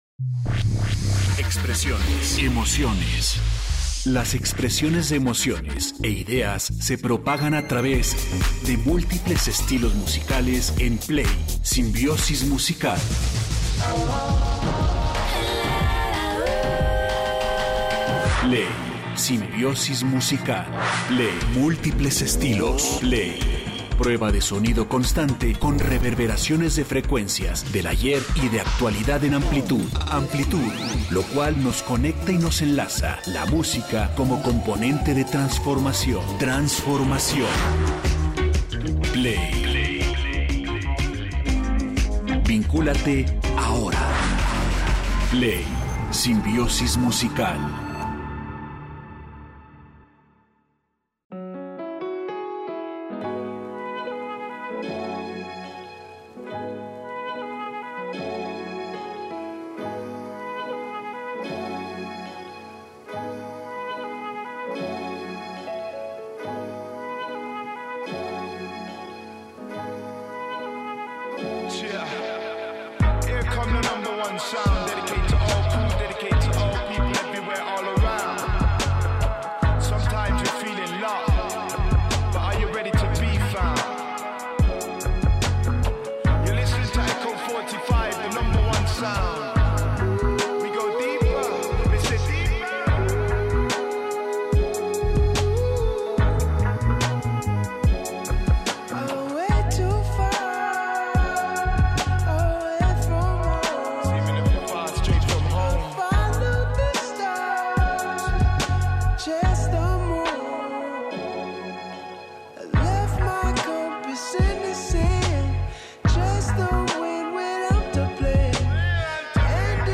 Déjate llevar por esta simbiosis musical que prende, vibra… y no suelta. 104.3 FM